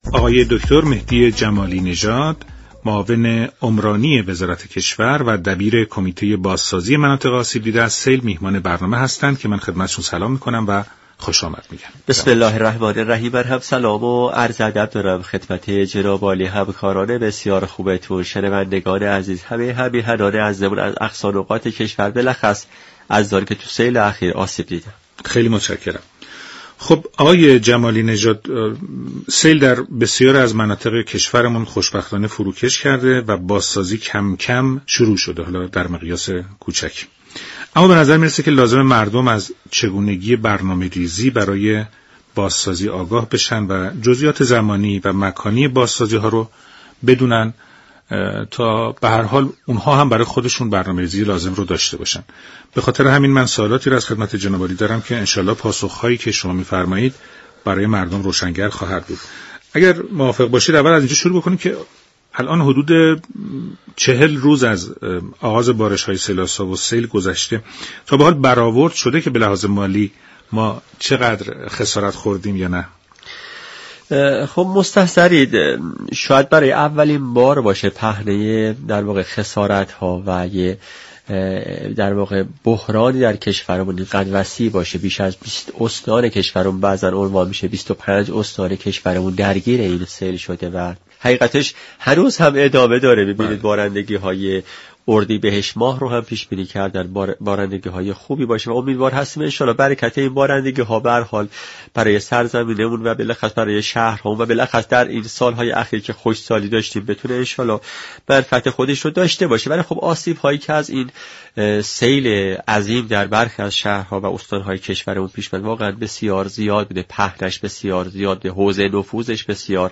معاون عمرانی وزیر كشور در گفت و گو با رادیو ایران گفت: با همكاری بنیاد مسكن توانسته ایم برای كاهش میزان آسیب ها در حوادث آینده، مكان های مناسبی را برای ساخت و سازها انتخاب كنیم.